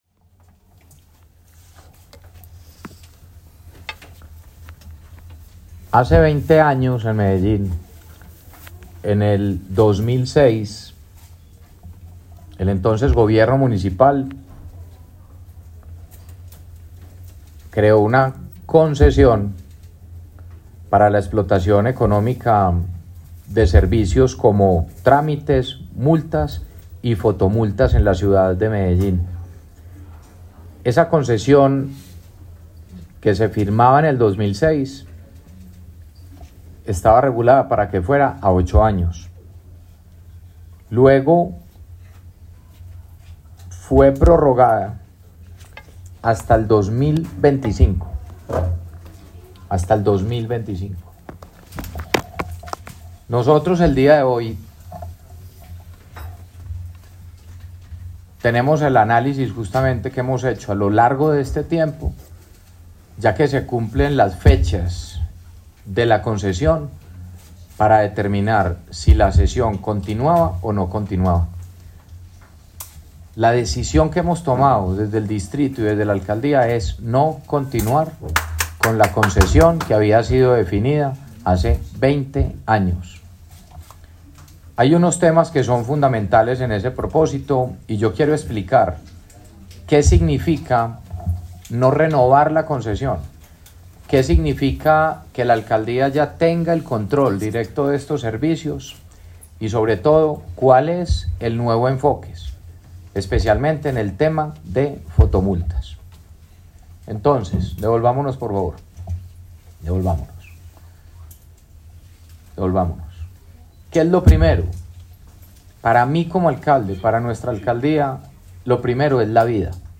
Declaraciones alcalde de Medellín Federico Gutiérrez
Declaraciones-alcalde-de-Medellin-Federico-Gutierrez-1.mp3